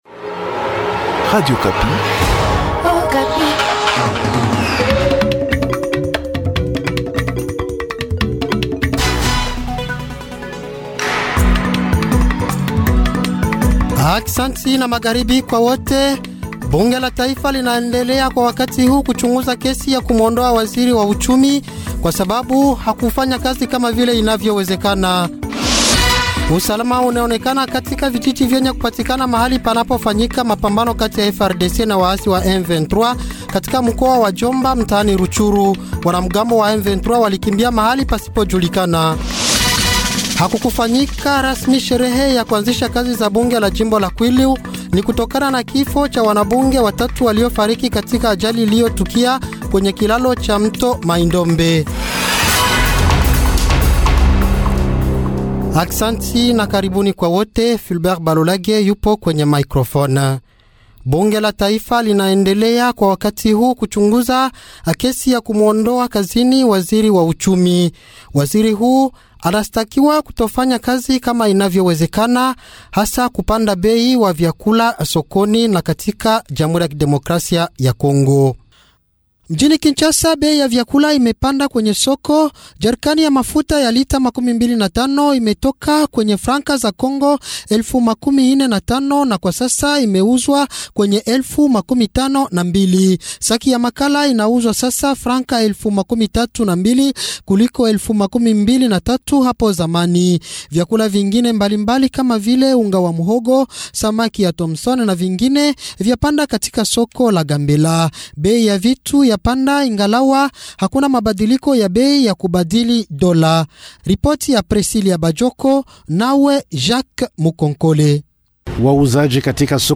Journal Swahili